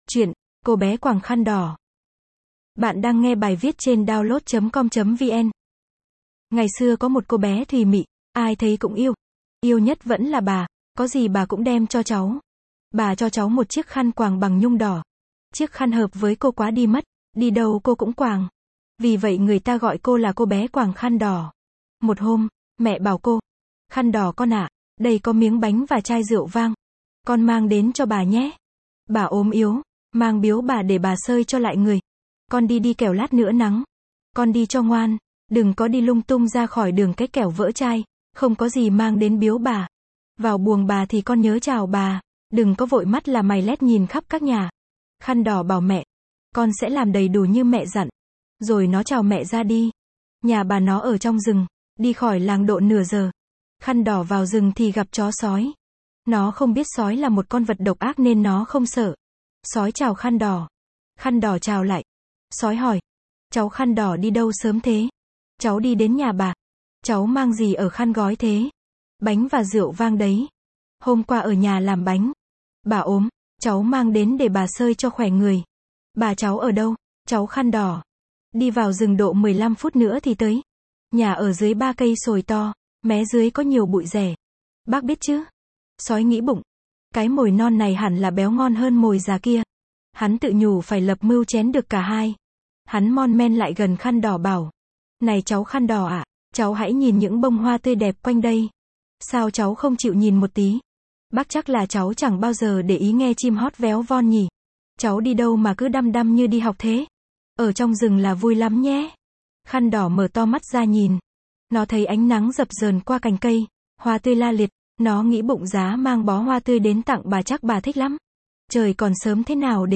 Sách nói | Cô bé quàng khăn đỏ